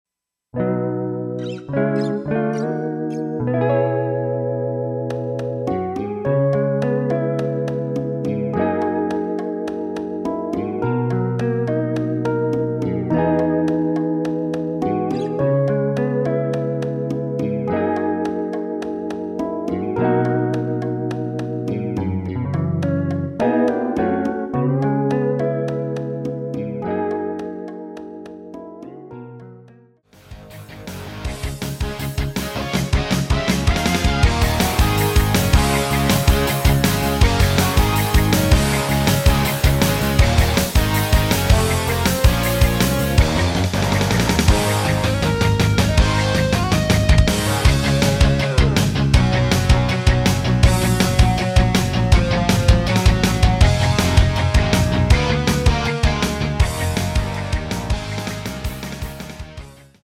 내린 MR
Rock으로 리메이크한 곡
◈ 곡명 옆 (-1)은 반음 내림, (+1)은 반음 올림 입니다.
앞부분30초, 뒷부분30초씩 편집해서 올려 드리고 있습니다.
중간에 음이 끈어지고 다시 나오는 이유는